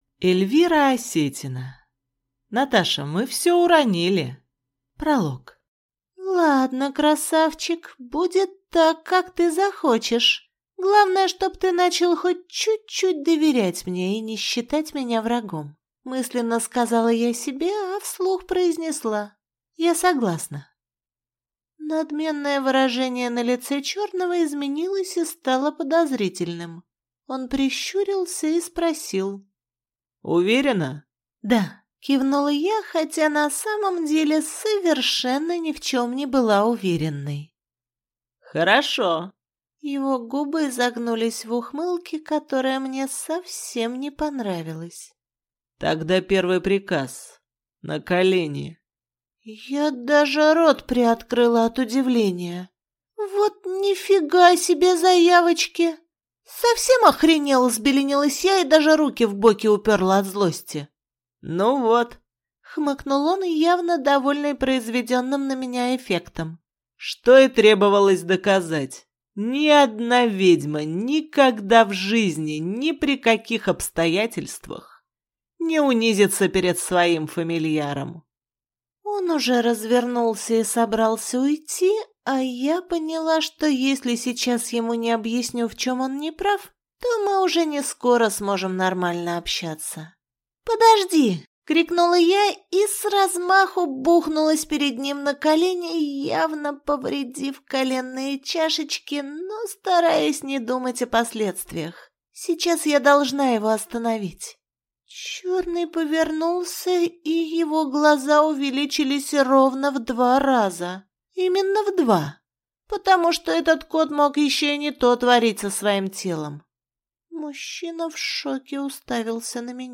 Аудиокнига Наташа, мы всё уронили!